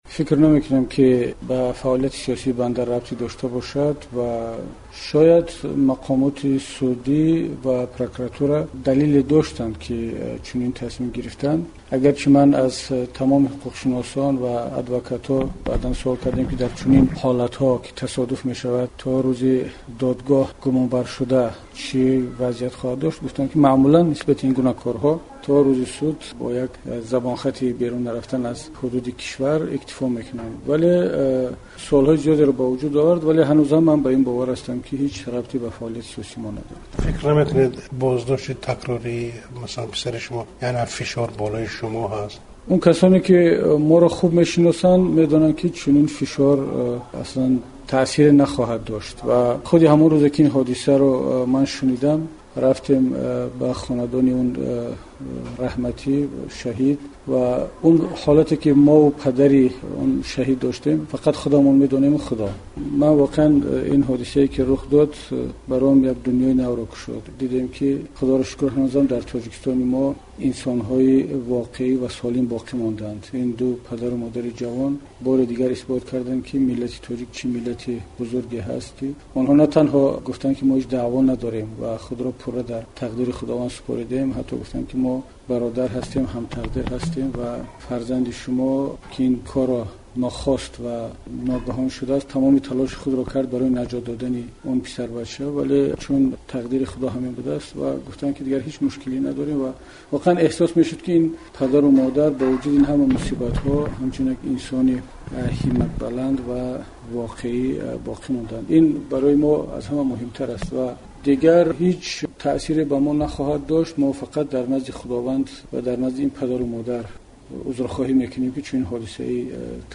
Гуфтугӯи хабарнигори "Озодӣ" бо Муҳиддин Кабирӣ, раиси ҲНИТ ва вакили Маҷлиси Намояндагони Тоҷикистон.